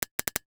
NOTIFICATION_Click_09_mono.wav